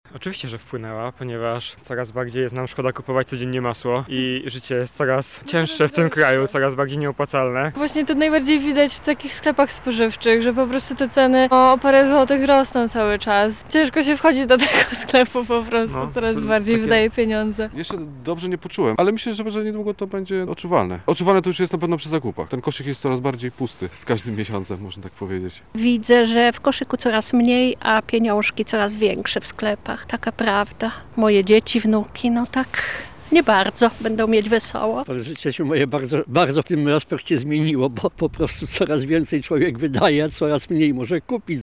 Zapytaliśmy bywalców Ogrodu Saskiego, czy i jak wysoka inflacja wpłynęła na ich życia:
SONDA